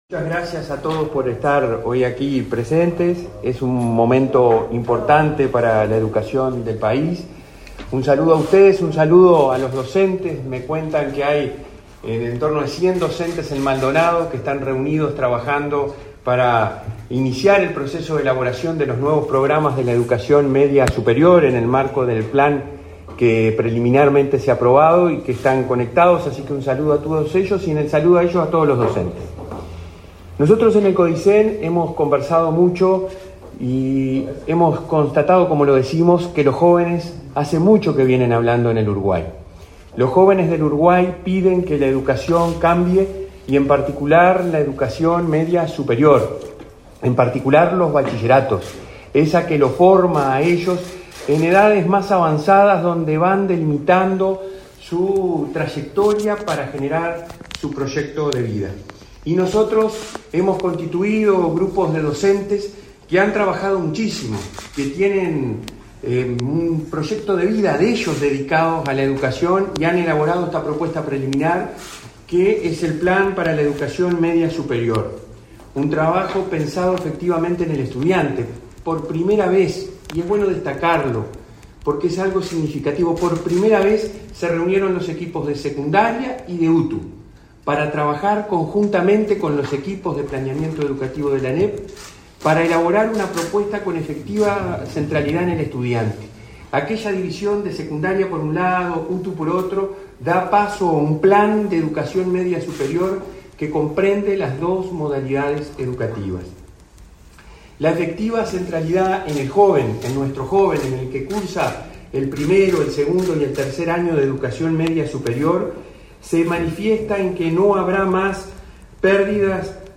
Palabras del presidente de la ANEP, Robert Silva
En la oportunidad, su presidente, Robert Silva, profundizó en una conferencia de prensa.